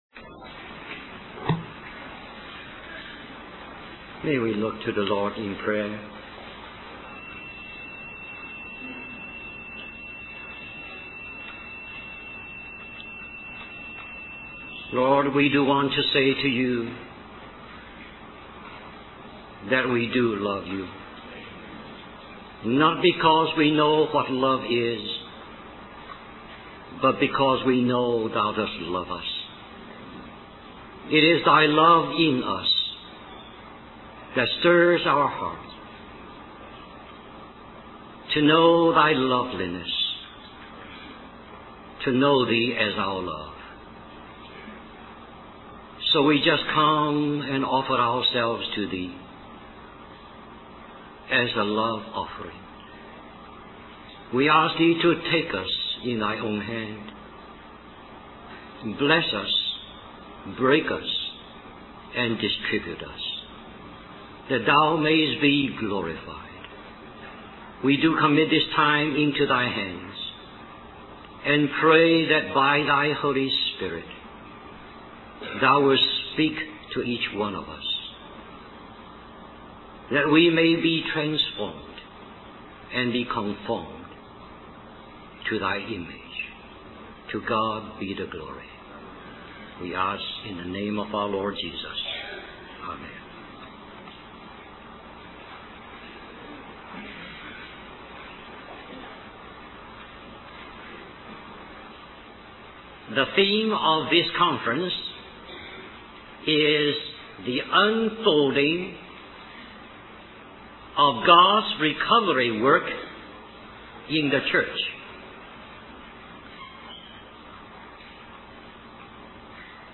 1987 Christian Family Conference Stream or download mp3 Summary This message is a partial recording.